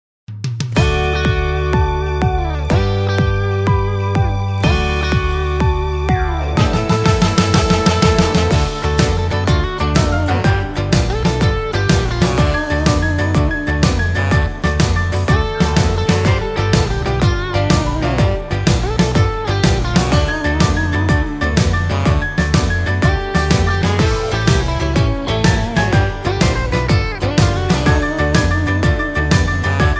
Singing Call
Inst